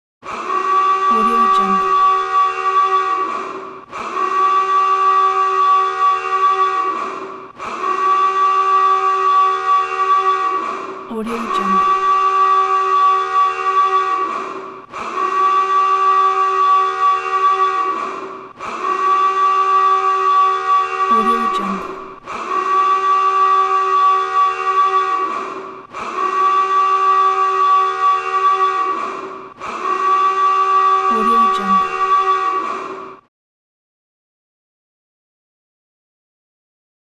دانلود افکت صدای آژیر خطر ایستگاه فضایی
افکت صدای آژیر خطر ایستگاه فضایی یک گزینه عالی برای هر پروژه ای است که به صداهای آینده نگر و جنبه های دیگر مانند هشدار، اعلان و زنگ هشدار نیاز دارد.
Sample rate 16-Bit Stereo, 44.1 kHz
Looped No